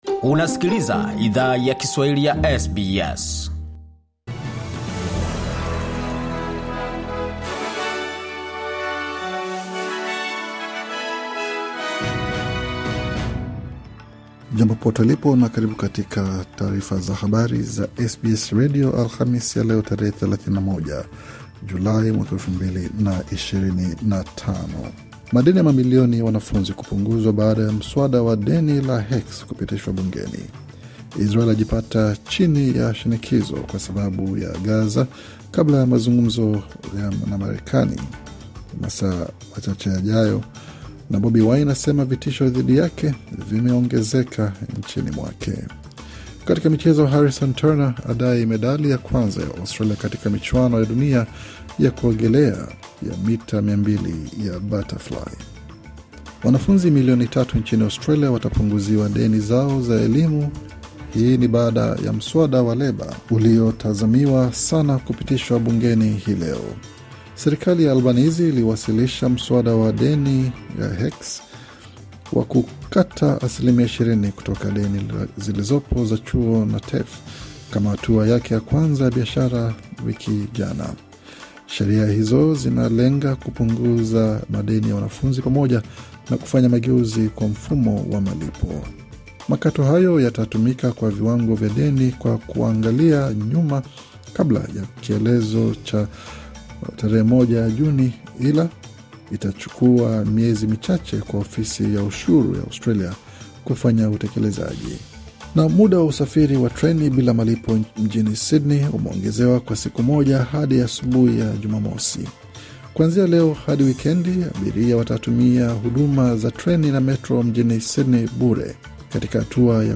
Taarifa ya Habari 31 Julai 2025